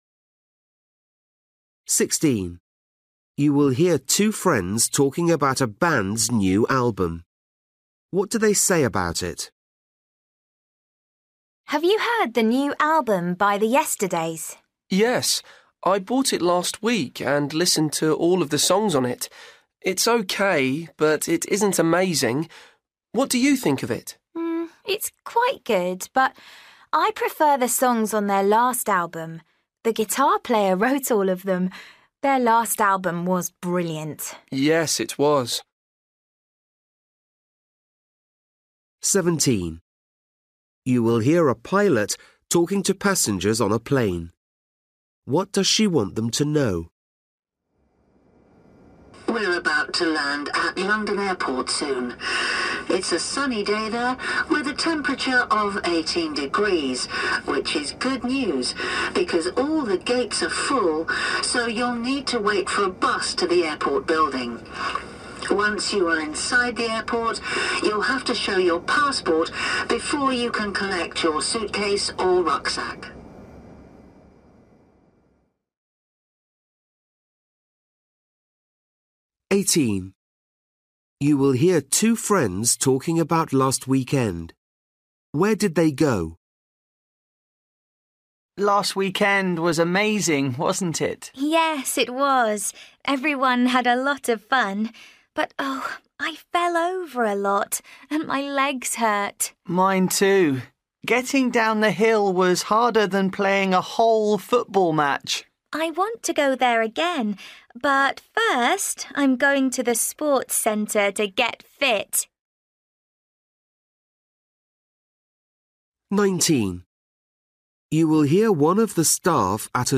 Listening: everyday short conversations
16   You will hear two friends talking about a bank’s new album. What do they say about it?
17   You will hear a pilot talking to passengers on a plane. What does she want them to know?
19   You will hear one of the staff at a zoo giving a talk. What does he want visitors to do?